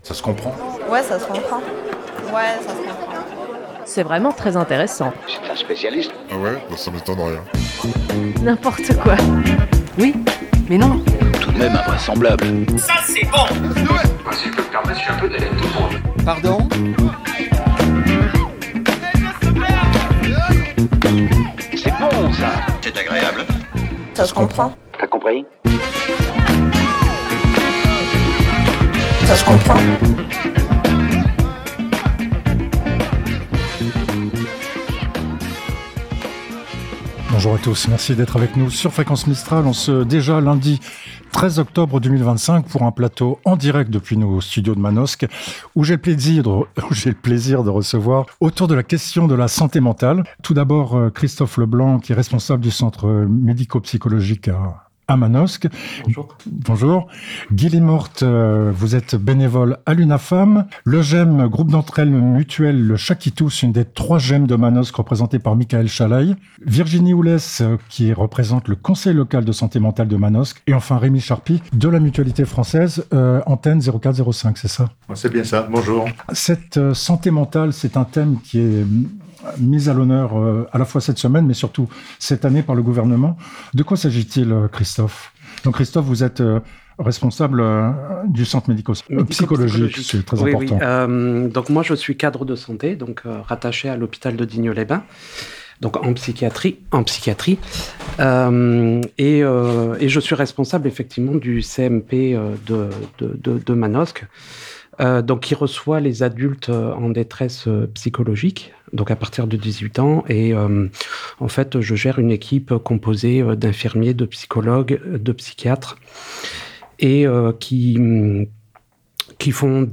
Des invités étaient présents dans le studio manosquin de Fréquence Mistral, lundi 13 octobre, pour débattre autour de cet enjeu majeur.